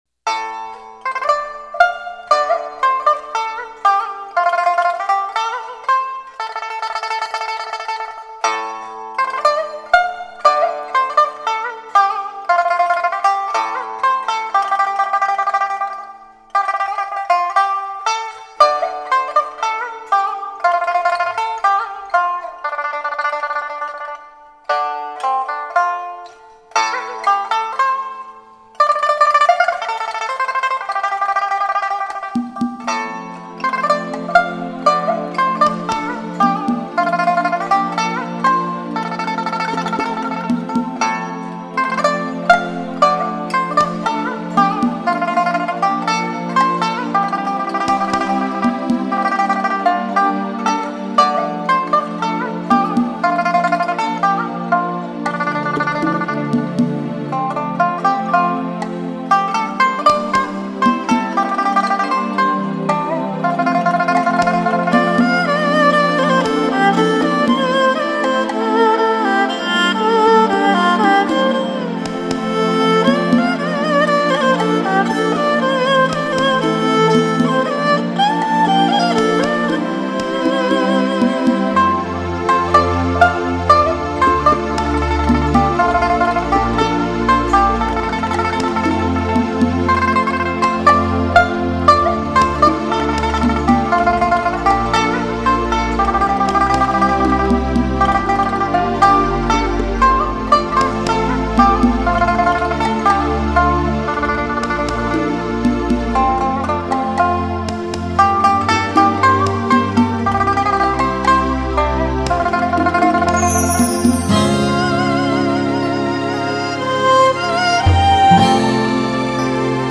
编曲、MIDI、键盘